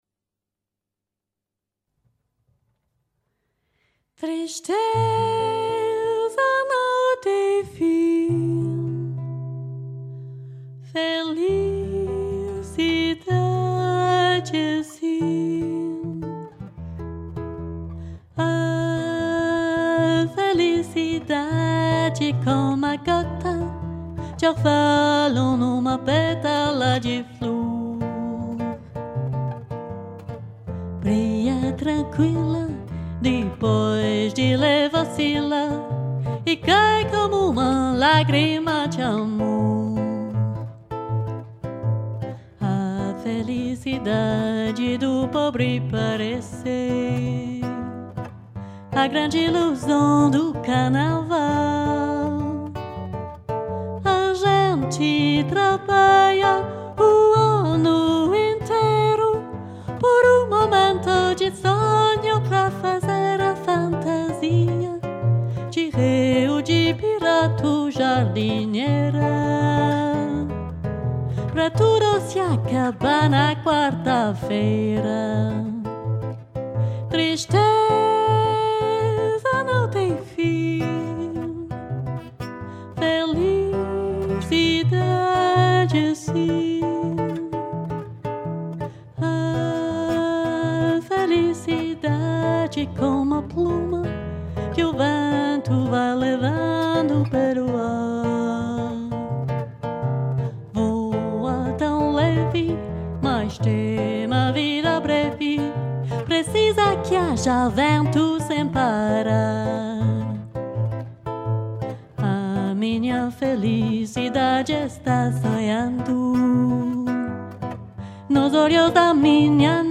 35 - 45 ans - Mezzo-soprano